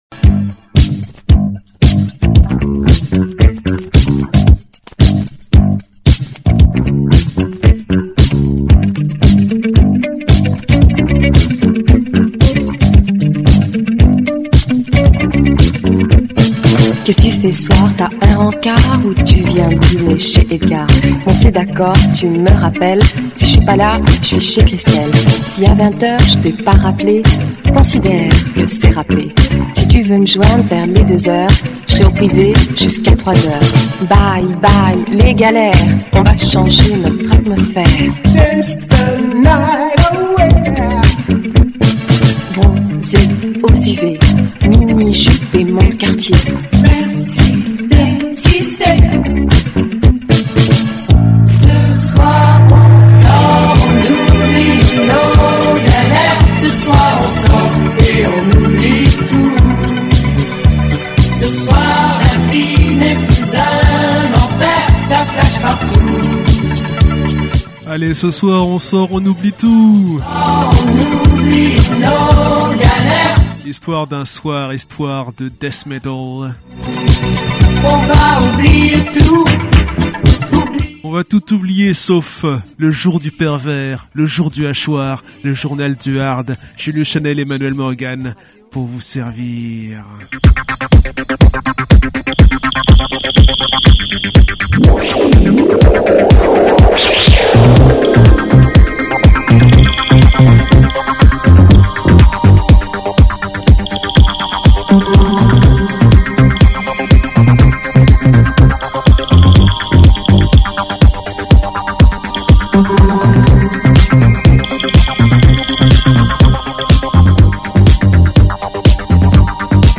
Mais revenons au sommaire de cet épisode ljdhien, résolument old school.